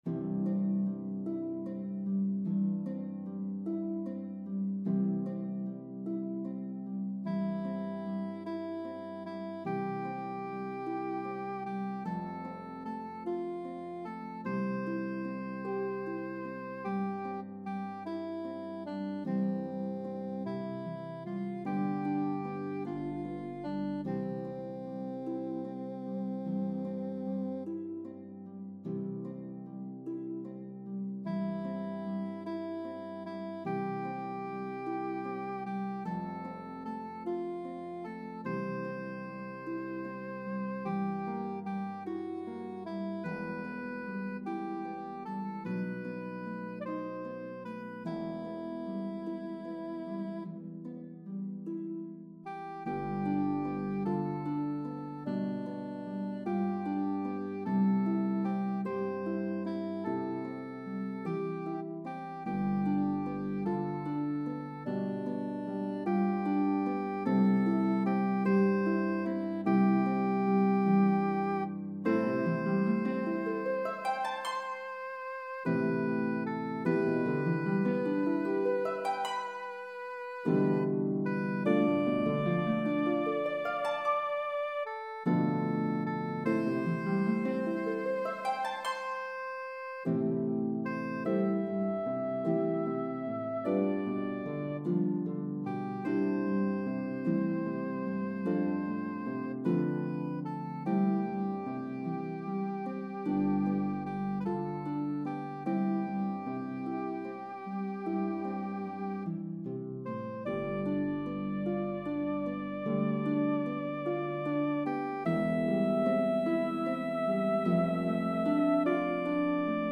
The melody of the two verses varies in rhythm & pitches.